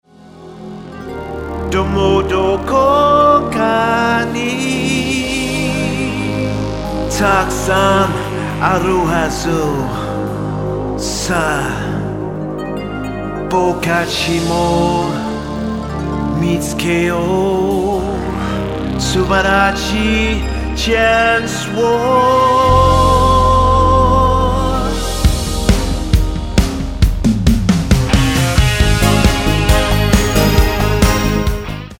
Tonart:Ab-A Multifile (kein Sofortdownload.
Die besten Playbacks Instrumentals und Karaoke Versionen .